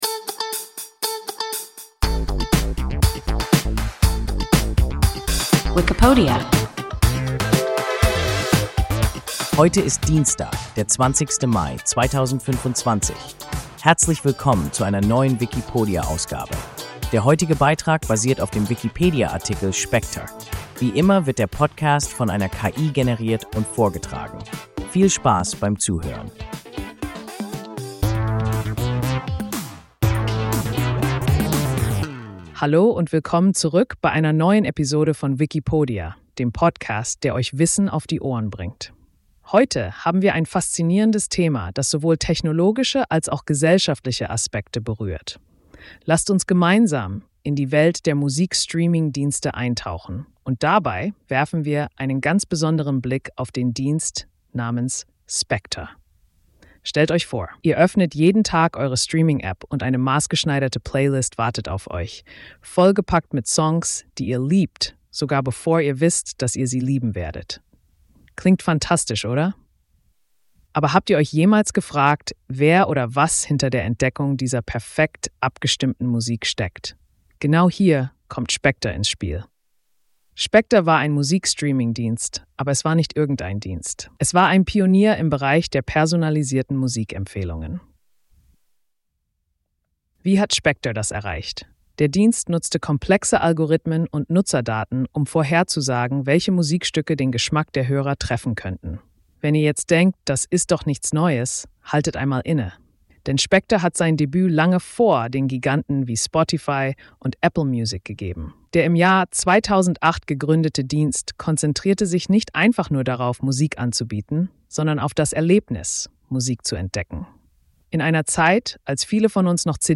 Spektr – WIKIPODIA – ein KI Podcast